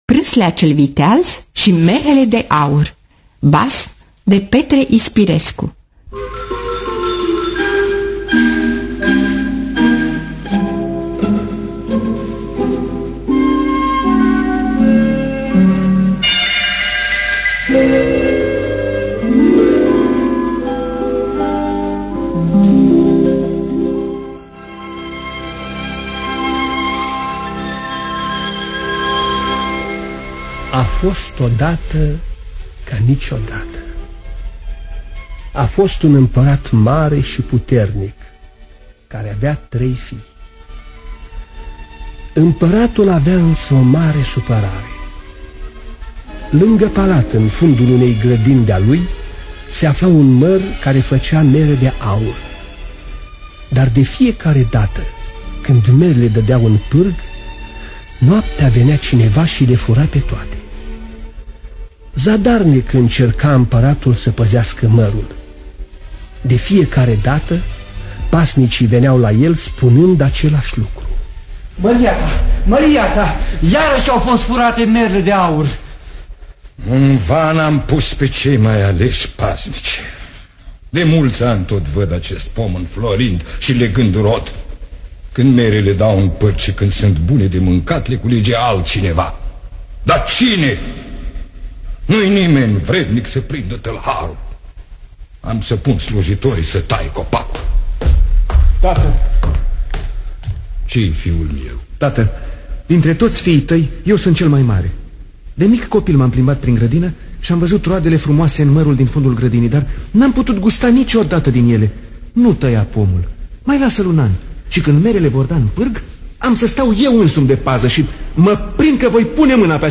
Petre Ispirescu – Praslea Cel Viteaz Si Merele De Aur (1965) – Teatru Radiofonic Online